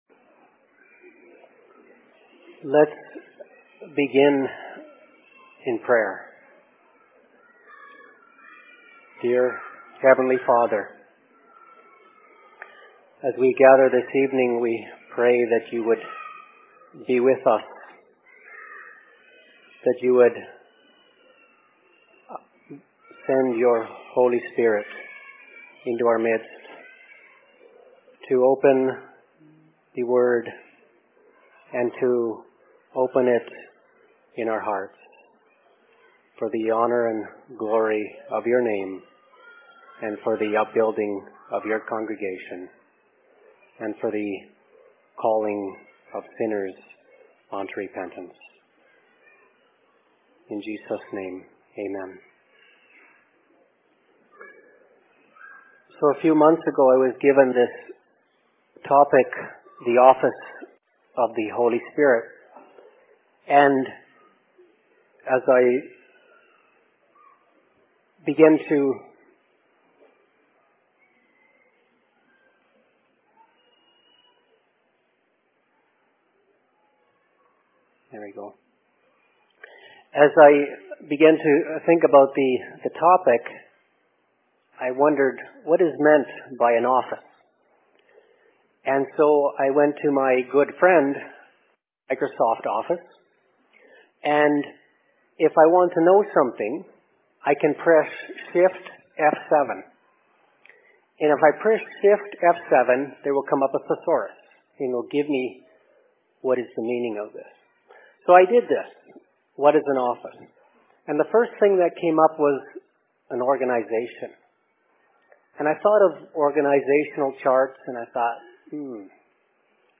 Youth Discussion/Presentation in LLC Summer Services, Essa, Ontario, Canada 03.07.2016
Location: LLC 2016 Summer Services